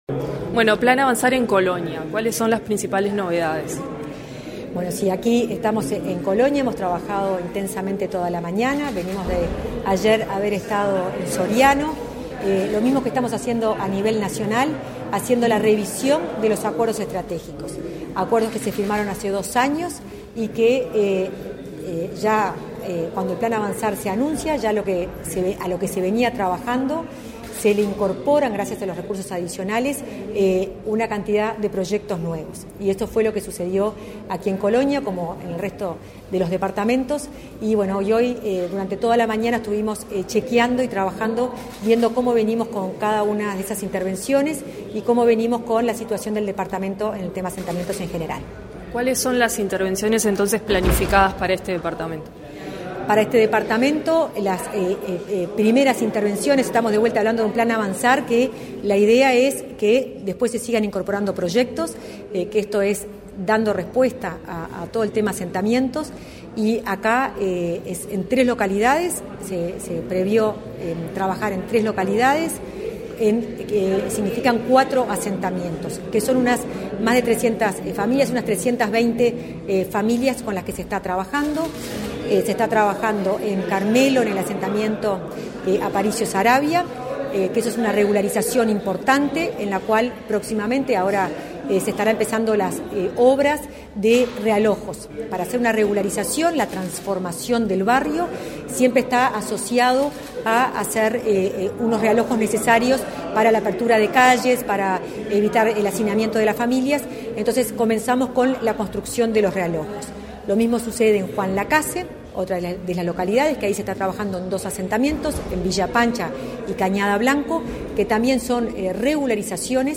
Entrevista a la directora del MVOT, Florencia Arbeleche
arbeleche prensa.mp3